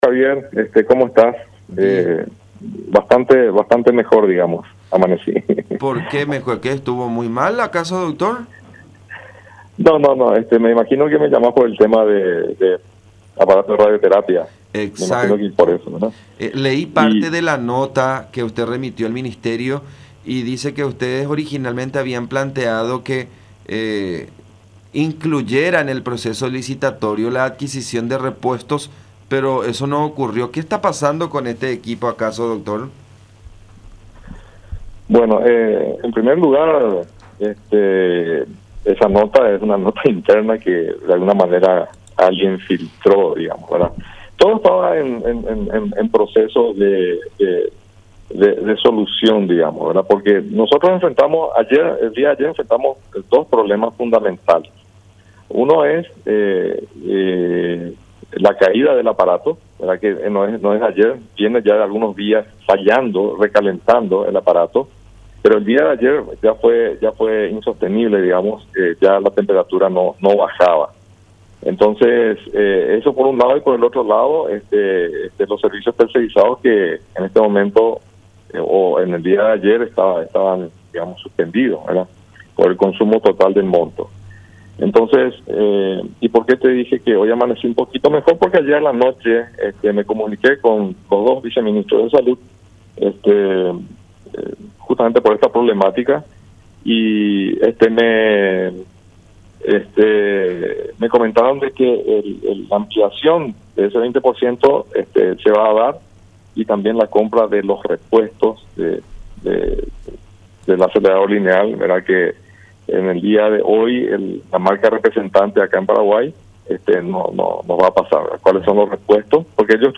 “El equipo de radioterapia estaba con algunas fallas, pero ayer la situación fue mucho más complicada. Es un pedido desesperado en nombre de los pacientes que necesitan de tratamiento y nosotros no tenemos las armas para dar respuestas.”, manifestó el Dr. Nelson Mitsui, director del INCAN, en contacto con La Unión.